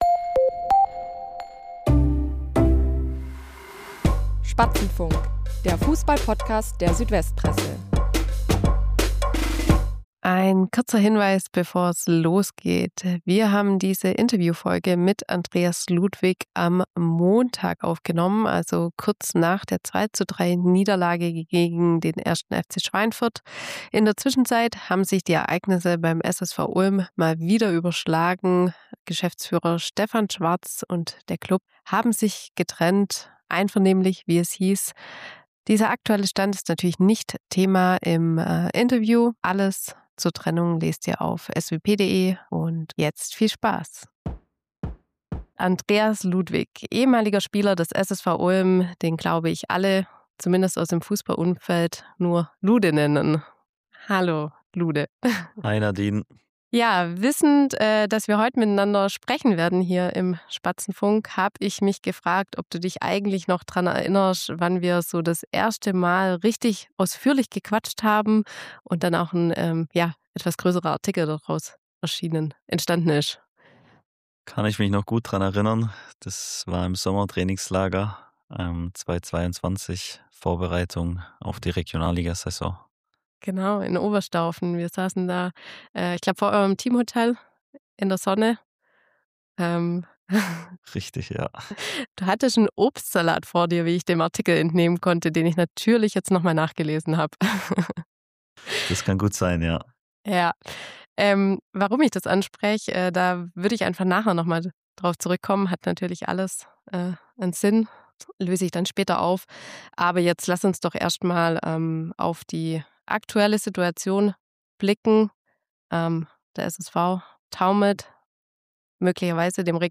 Interview ~ Spatzenfunk